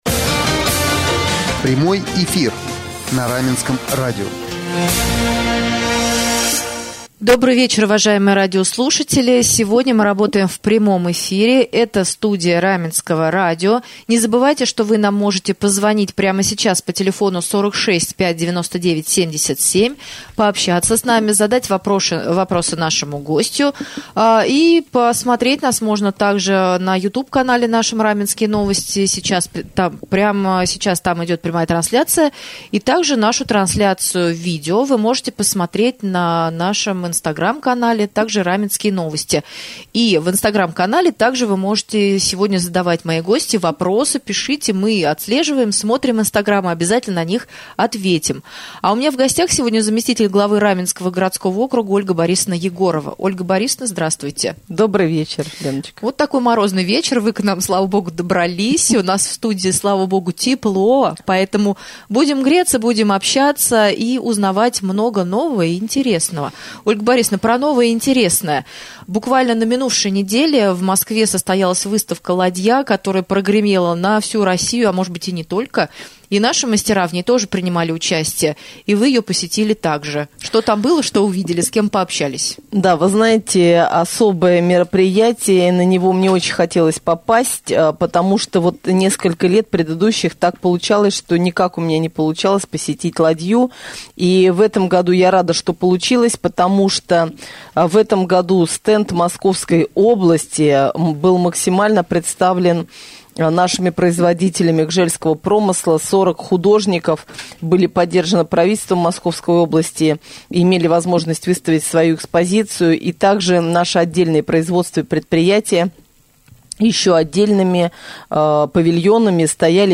В среду, 22 декабря, гостьей прямого эфира на Раменском радио стала заместитель Главы Раменского г.о. Ольга Борисовна Егорова.